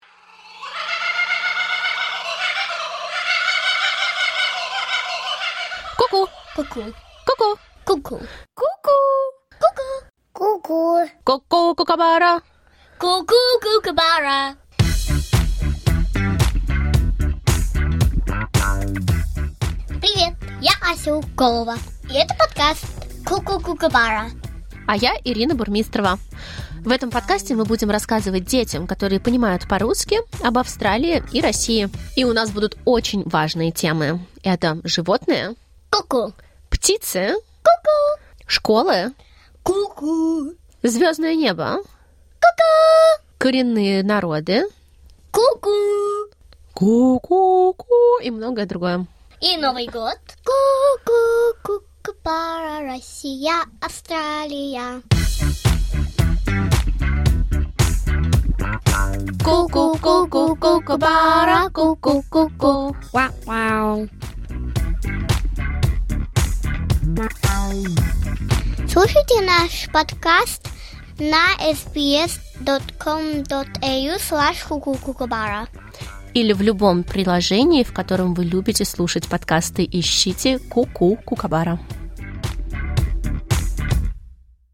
"Koo-koo, kookaburra!" is a podcast for children about how different life is in Russia and Australia.
We tell stories, sing, laugh, wonder and learn from each other.